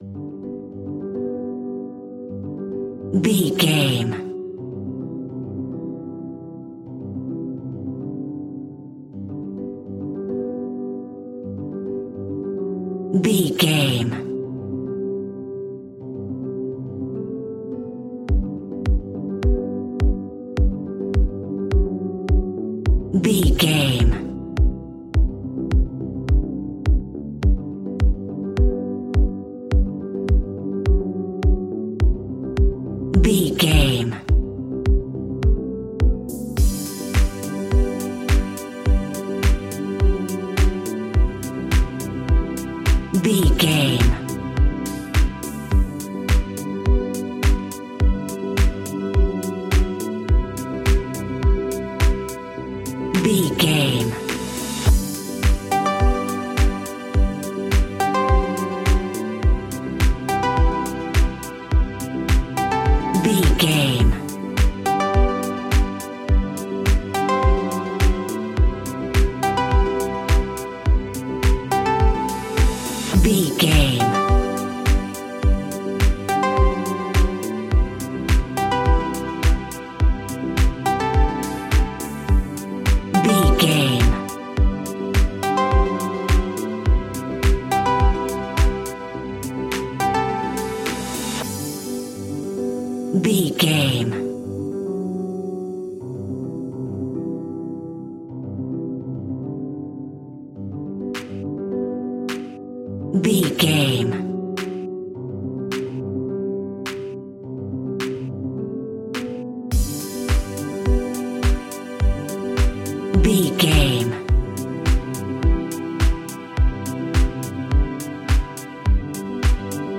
Ionian/Major
groovy
uplifting
energetic
cheerful/happy
repetitive
synthesiser
drums
electric piano
strings
harp
electronic
synth bass